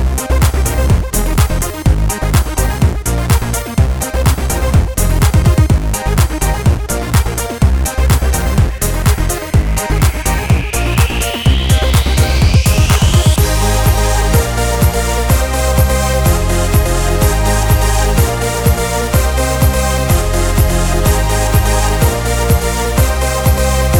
Male Solo Mix R'n'B / Hip Hop 3:43 Buy £1.50